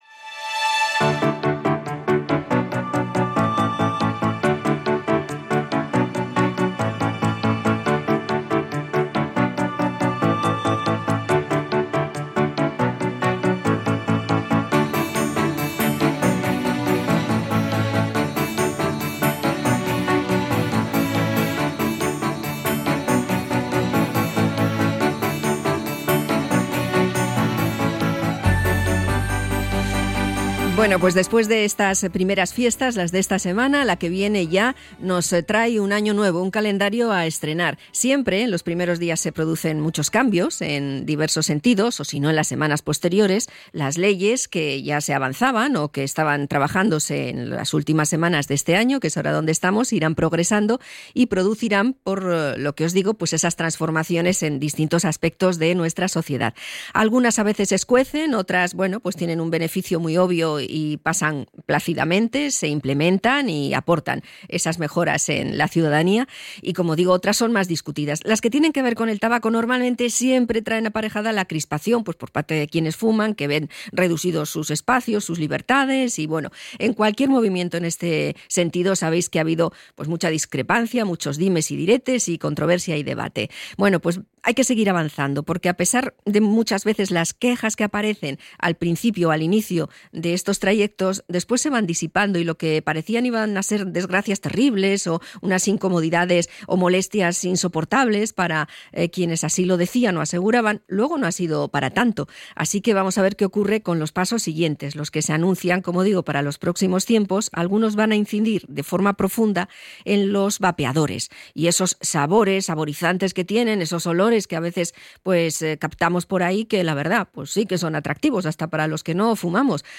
INT.-DECRETO-VAPEO.mp3